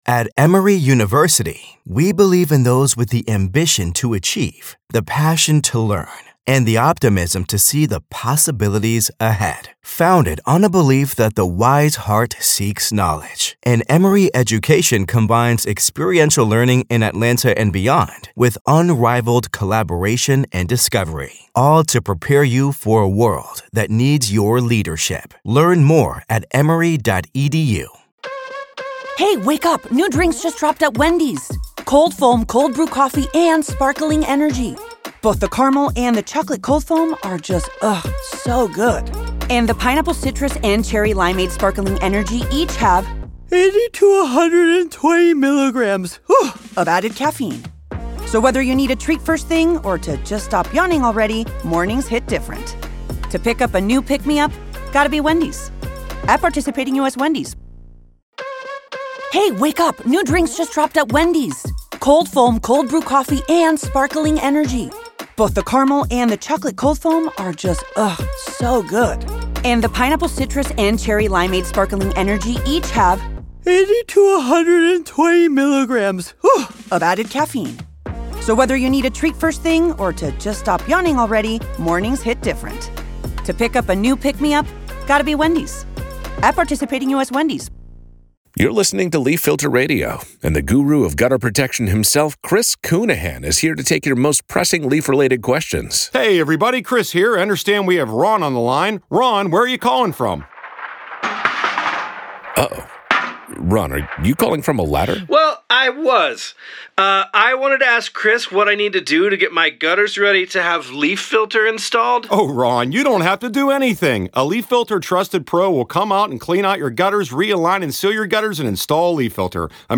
reads excerpts from The Book of Trump